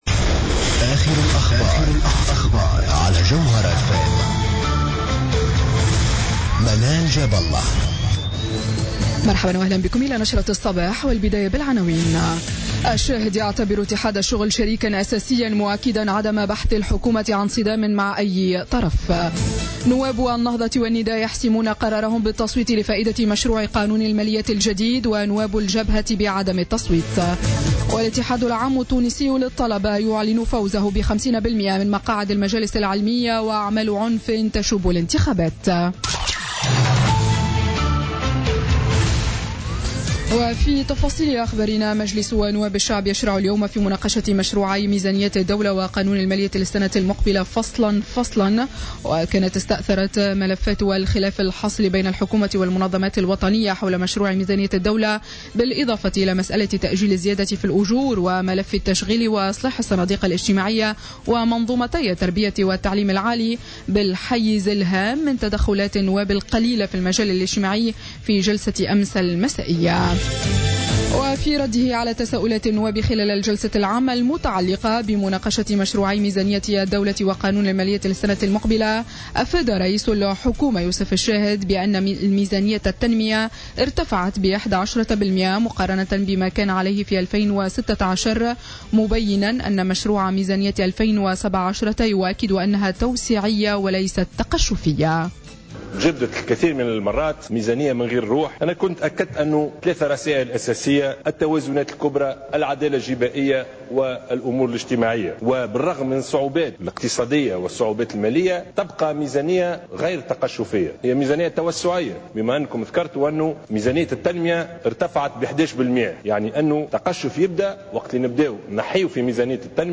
نشرة أخبار السابعة صباحا ليوم السبت 19 نوفمبر 2016